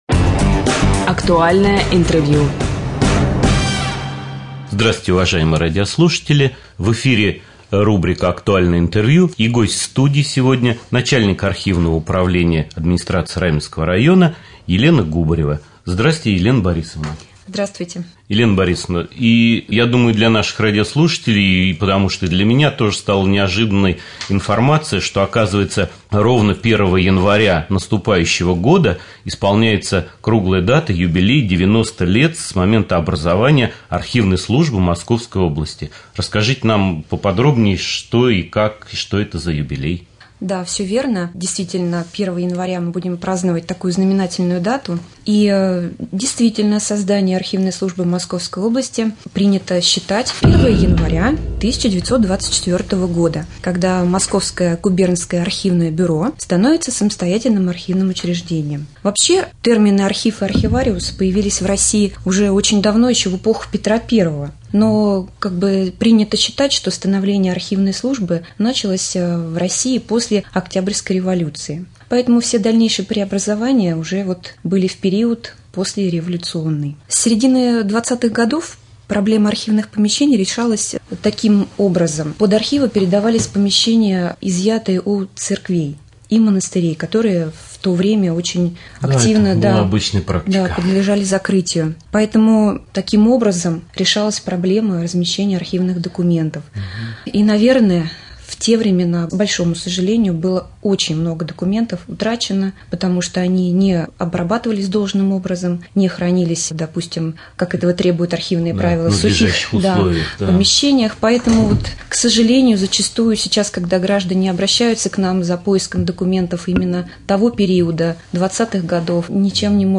2.Рубрика «Актуальное интервью».